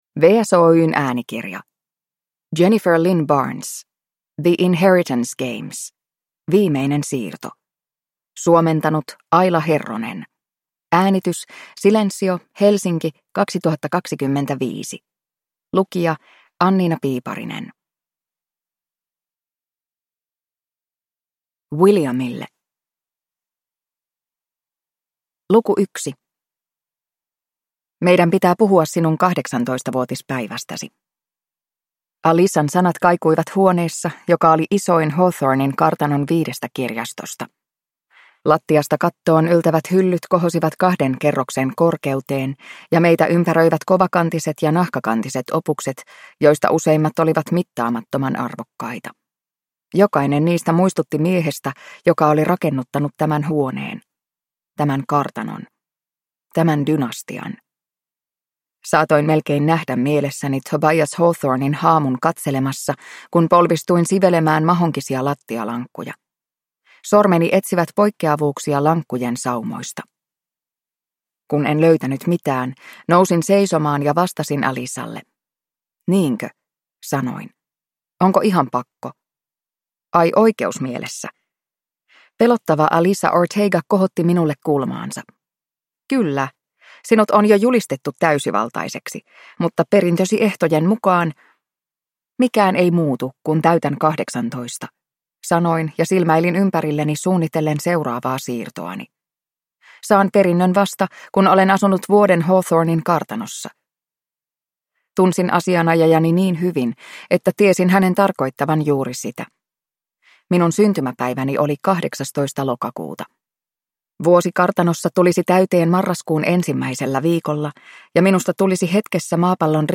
The Inheritance Games: Viimeinen siirto (ljudbok) av Jennifer Lynn Barnes